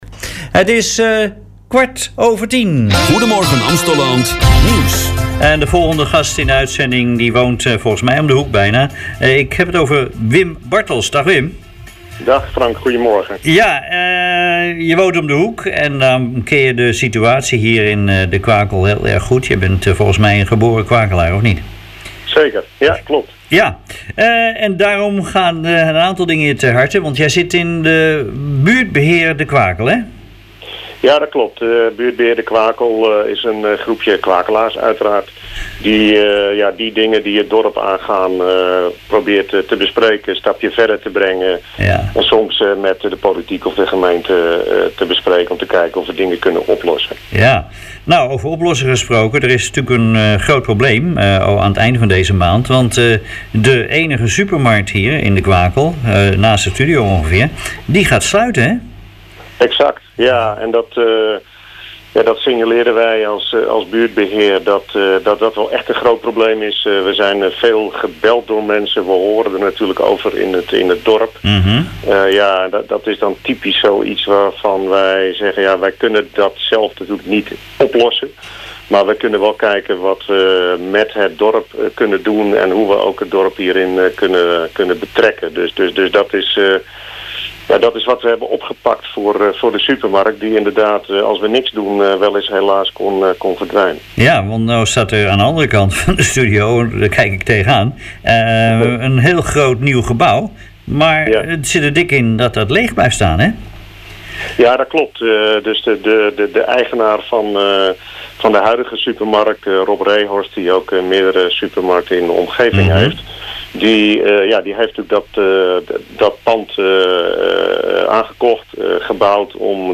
In een interview met Rick FM vertelt hij dat de zorgen in het dorp leven als nooit tevoren.